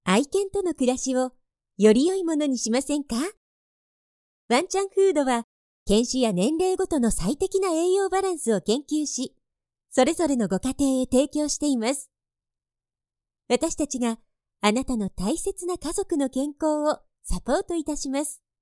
テレビ・ラジオ、CM、企業VP、ゲーム、施設内放送など、さまざまなジャンルで多くの実績があり、明るく爽やかなもの、堅く落ち着いたもの、優しい語りまで、幅広く対応可能です。
– ナレーション –
female109_26.mp3